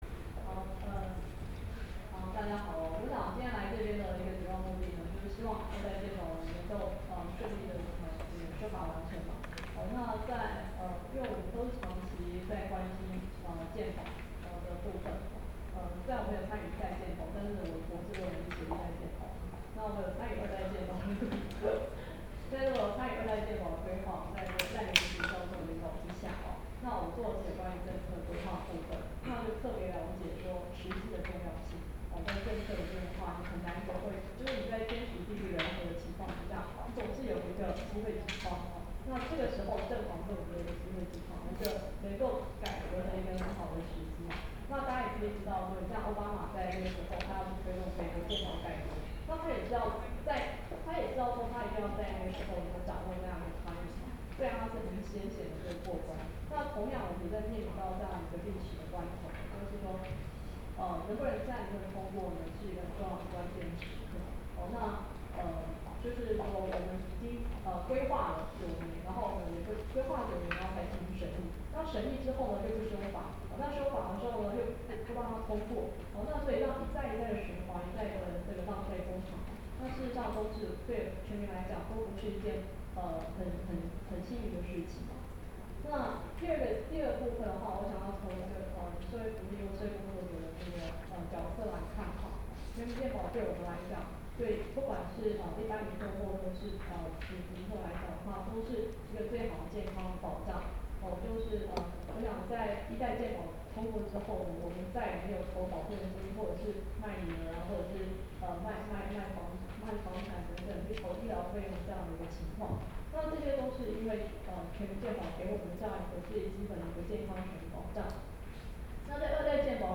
「健保改革不能退怯  修法時機必須掌握」記者會
發言錄音檔